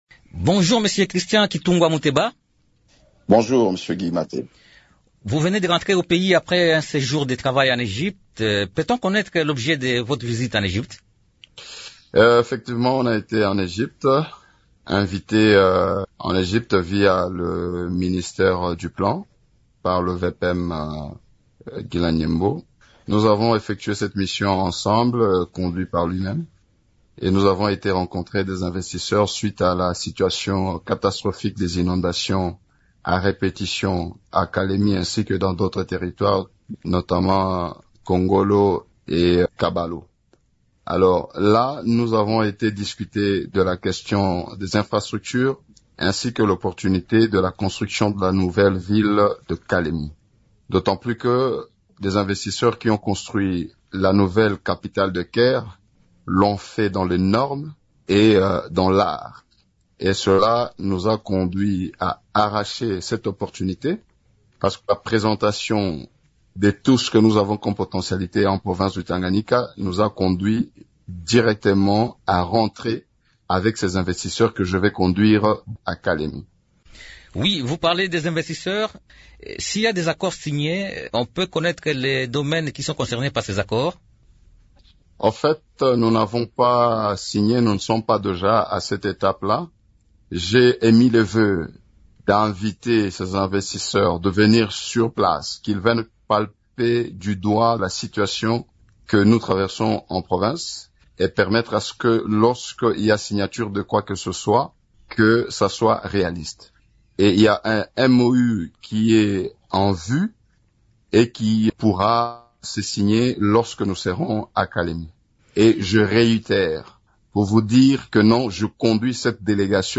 Dans un entretien accordé dimanche 20 juillet à Radio Okapi, le gouverneur a souligné que les villes de Kalemie, Kongolo et Kabalo sont régulièrement confrontées à des inondations récurrentes, perturbant la vie des habitants et la croissance économique locale.